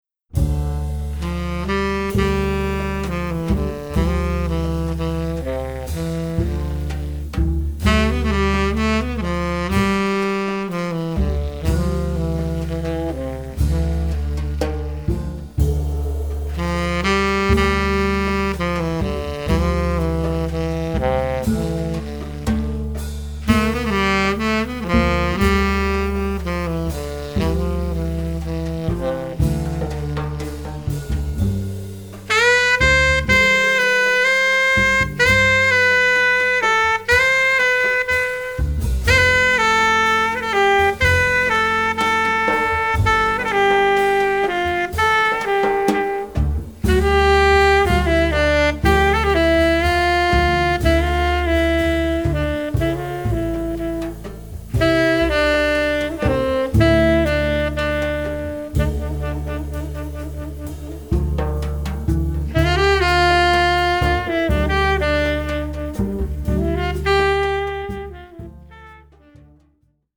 Genre: Jazz.
Traditional Turkish Folksong
an odd, monaural sound
a hypnotic, slow-paced Turkish folk song